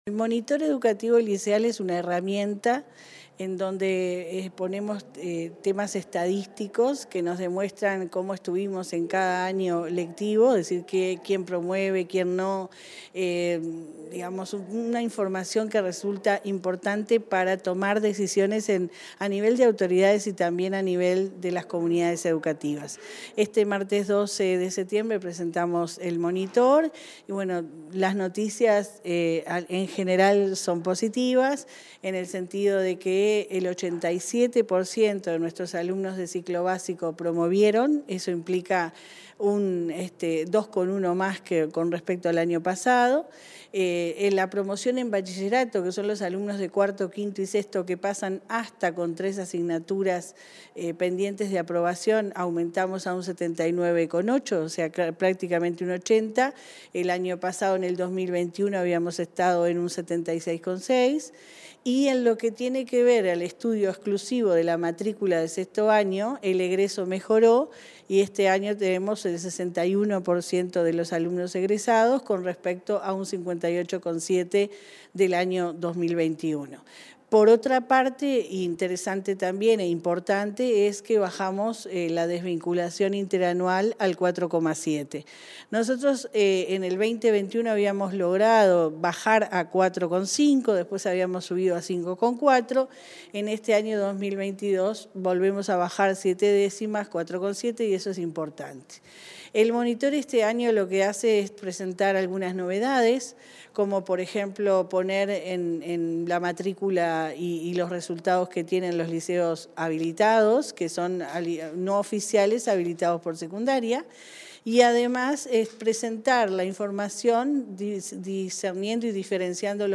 Entrevista a la directora general de Secundaria, Jenifer Cherro